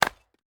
Chopping and Mining
chop 2.ogg